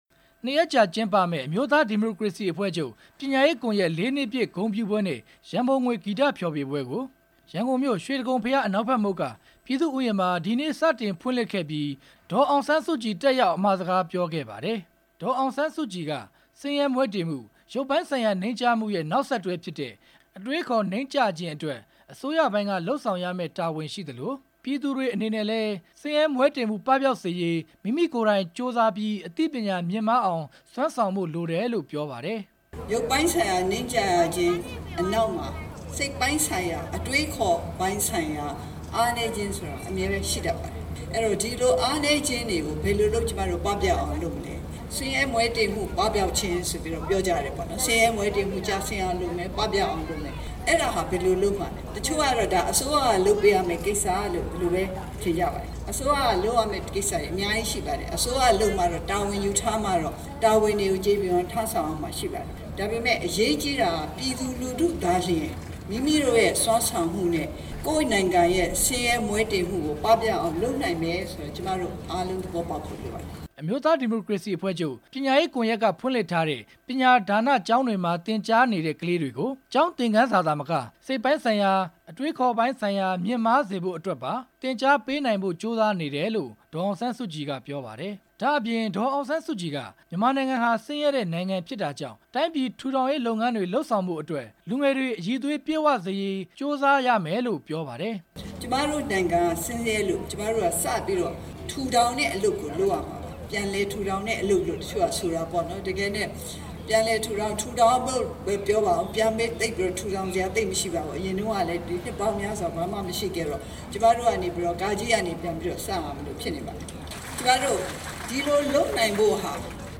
ဒေါ်အောင်ဆန်းစုကြည်ရဲ့ ပြောကြားချက်များ
ရန်ကုန်မြို့ ပြည်သူ့ရင်ပြင်မှာ ဒီကနေ့ကျင်းပတဲ့ အမျိုးသားဒီမိုကရေစီအဖွဲ့ချုပ် ပညာရေးကွန်ရက် ၄ နှစ်ပြည့် ဂုဏ်ပြုပွဲနဲ့ ရန်ပုံငွေဂီတ ဖျော်ဖြေပွဲဖွင့်ပွဲ အခမ်းအနားမှာ ဒေါ်အောင်ဆန်းစုကြည်က အခုလို ပြောကြားခဲ့တာပါ။